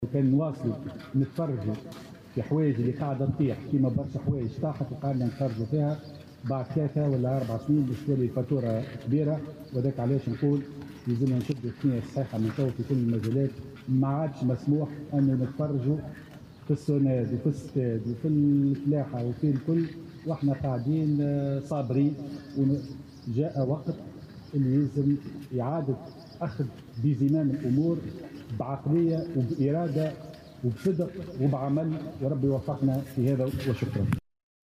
وأوضح على هامش زيارته اليوم إلى ولاية جندوبة أنه لم يعد من المسموح أن تتواصل وضعية بعض المؤسسات العمومية على غرار "الستاغ" و"الصوناد" على ما هي عليه، مضيفا " كان نواصلو نتفرجوا في الحوايج اللي قاعدة تطيح، بعد 3 او 4 سنين الفاتورة باش تولي كبيرة ويلزمنا نشدو الثنية الصحيحة في جميع المجالات".